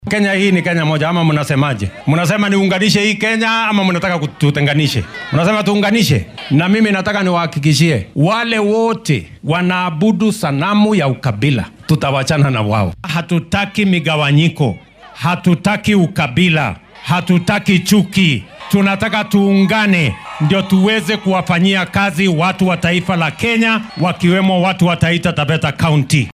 Madaxweynaha dalka William Ruto ayaa ka digay siyaasadda ku saleysan qabyaaladda iyo kala qaybinta shacabka. Xilli uu maanta ku sugnaa ismaamulka Taita Taveta